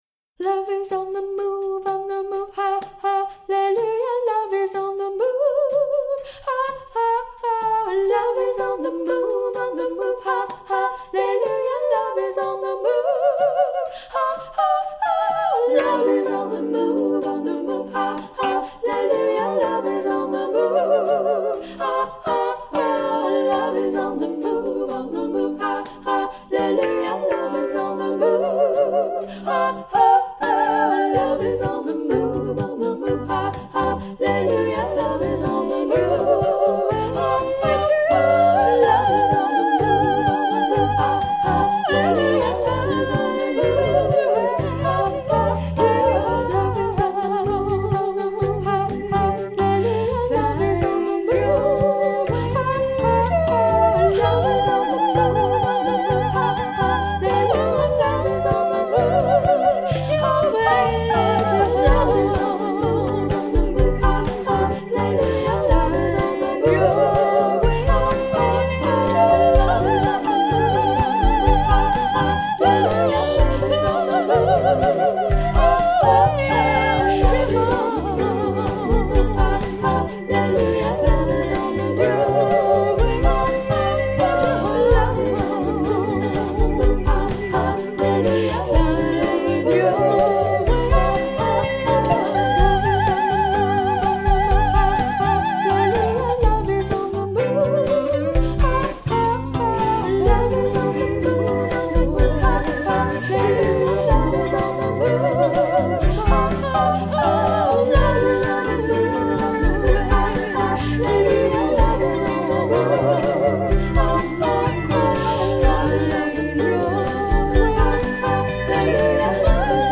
Vocals and rattle
Tambourine
Electric guitar and bass, organ, keyboard, and Soundscape